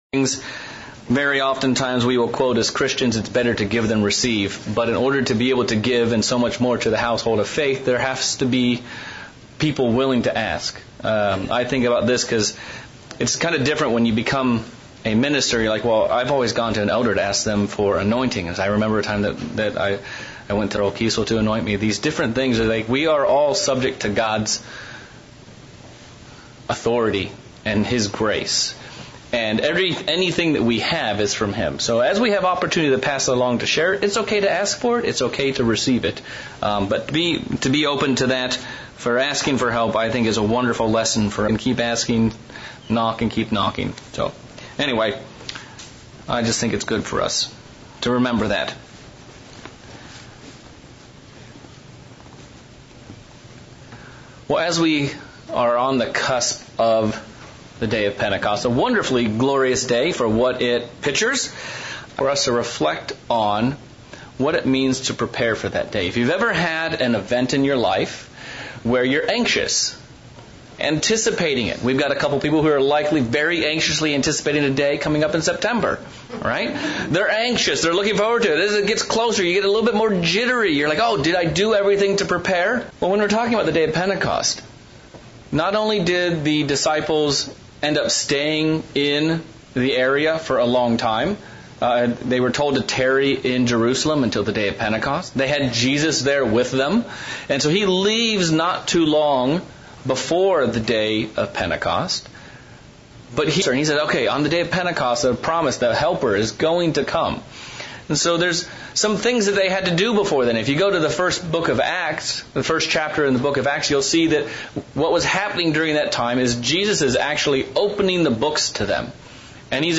Sermon looking at Christ's conversations with the Pharisees about having a clean dish. The vessel might look really good on the outside, but what does the inside of the dish look like?